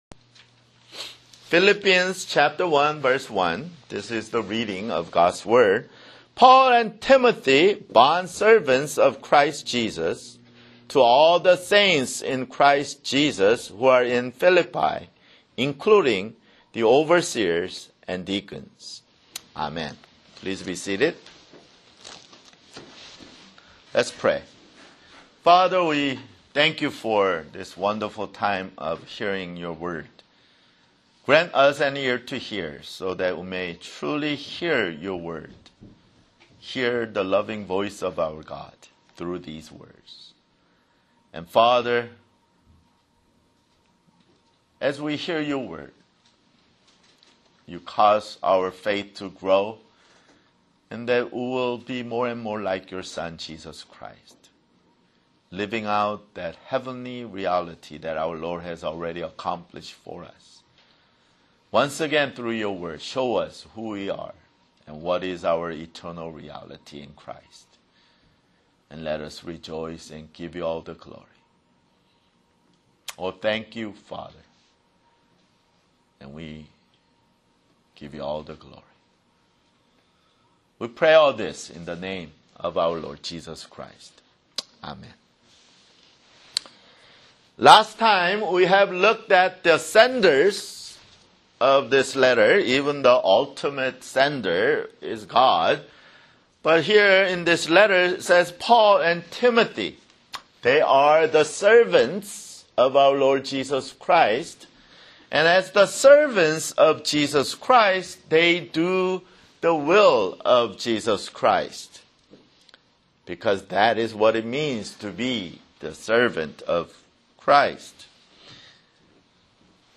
[Sermon] Philippians (3)